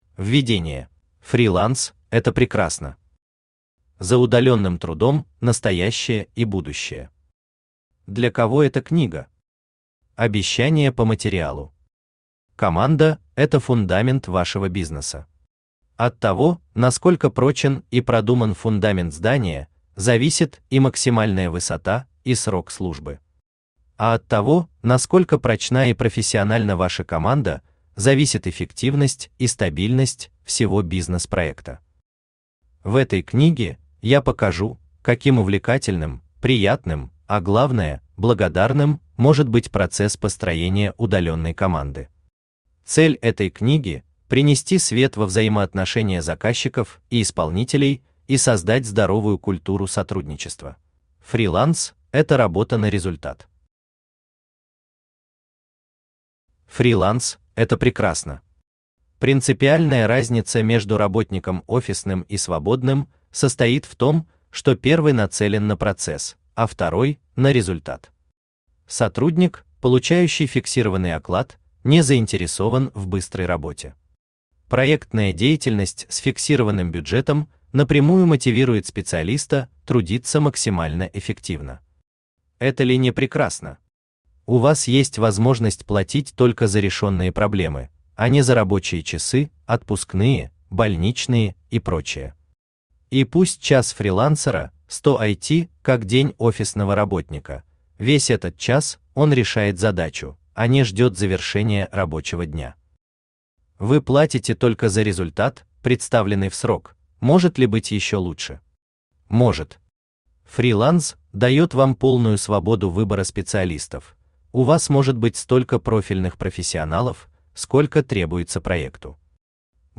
Аудиокнига Моя удаленная команда | Библиотека аудиокниг
Aудиокнига Моя удаленная команда Автор Алексей Красиков Читает аудиокнигу Авточтец ЛитРес.